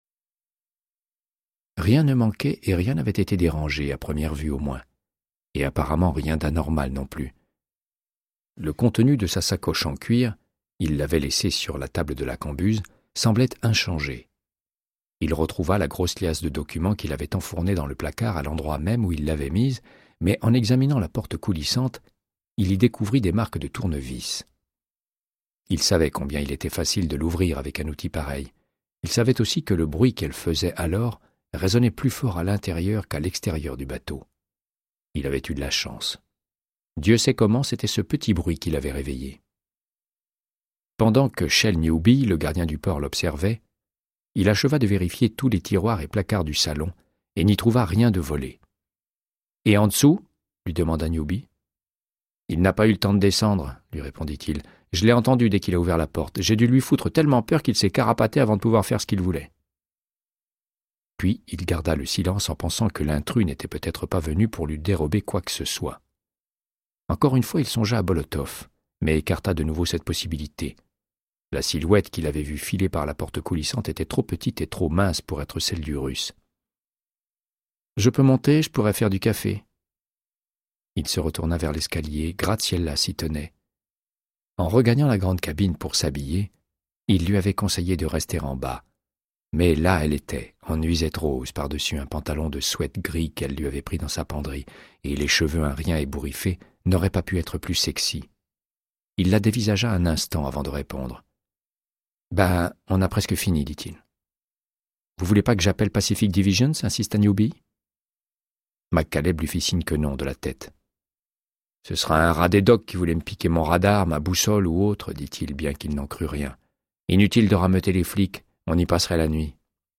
Audiobook = Créance de sang, de Michael Connelly - 107